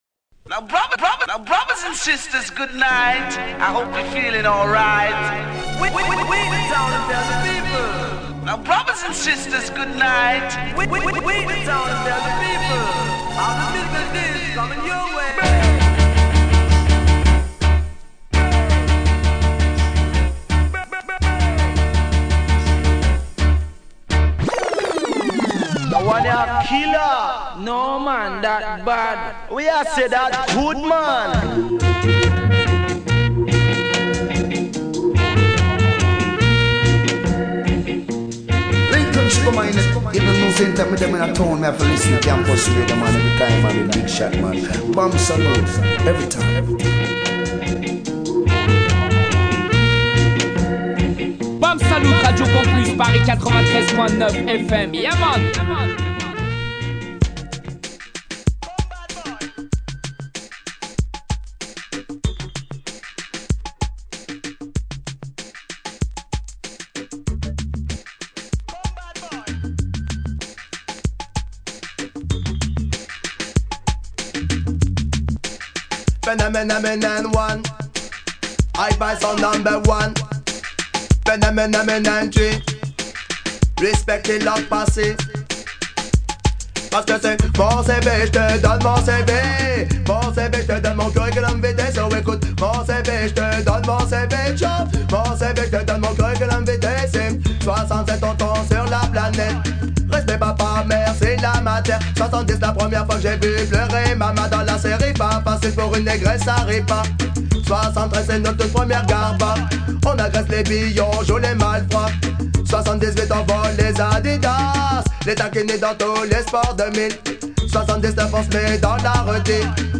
Une sélection spéciale Frenchies pour commencer, suivie d'une interview et enchaînée par un live and direct.